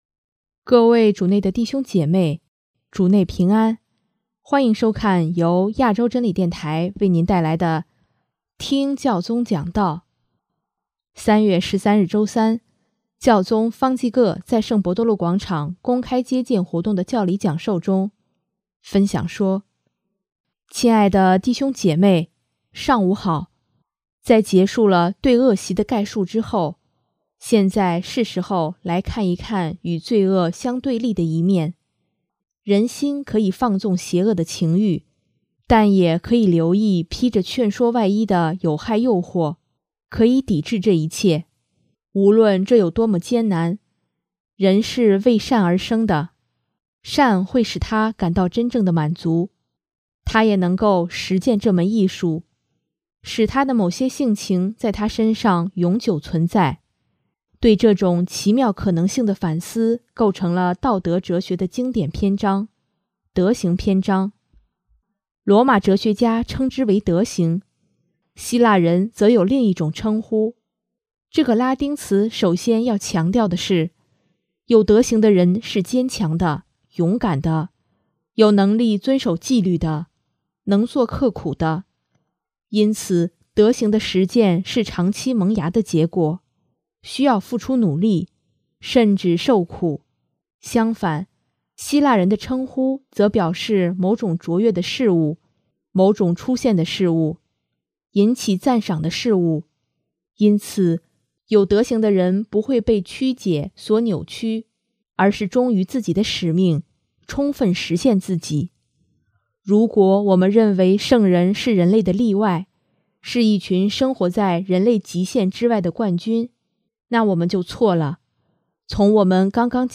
3月13日周三，教宗方济各在圣伯多禄广场公开接见活动的教理讲授中，分享说：